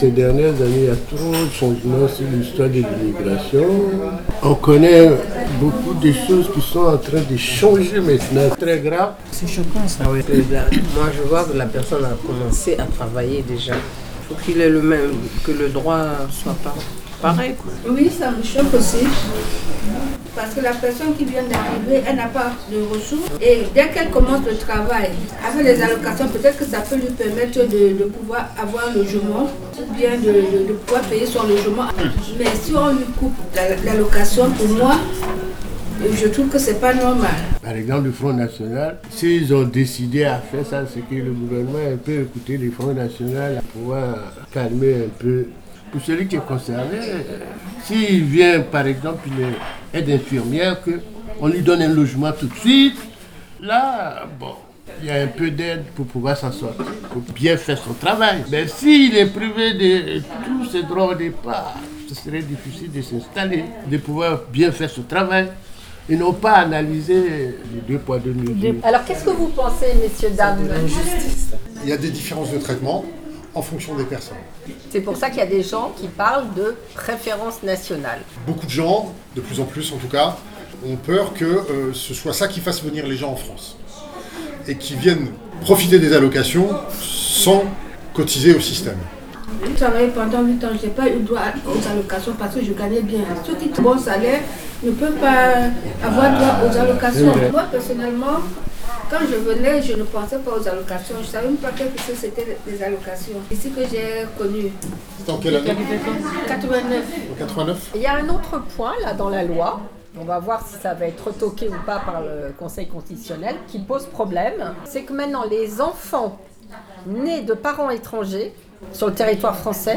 Pour le premier atelier de l’année ; il a été question de la nouvelle loi sur l’immigration votée par l’Assemblée nationale et retoquée pour l’essentiel par le Conseil Constitutionnel. L’occasion pour les adhérents du café social, presque tous venus d’ailleurs, de s’exprimer sur le contenu de la loi, la "préférence nationale" pour les allocations familiales et la perspective de la fin du droit du sol pour les nouveaux nés en France de pays étrangers. Tous se disent inquiets face au climat et au sentiment anti-étranger qui monte et dénoncent une instrumentalisation par les politiques de l’immigration.